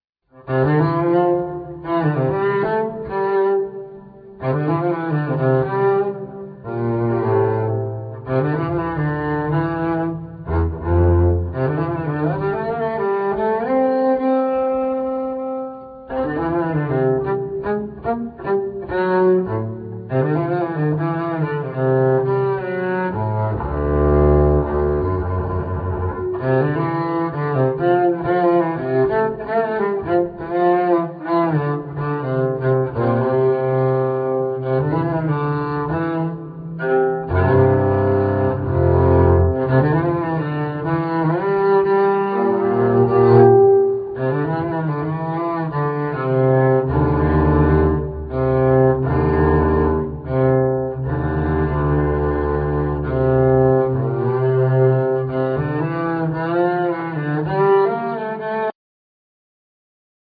Double bass,Piano